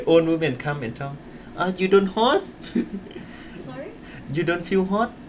S1 = Brunei female S2 = Laos male Context: S2 is talking about women wearing Islamic head covering and other women assuming they must feel hot.
The delayed release of the final [t] in hot might have contributed to the problem; but it is also likely because of the absence of a main verb in: 'you don't hot'. S1 finally understood him when he said 'you don't feel hot' (even though there is no [t] on the end of this second token of hot ).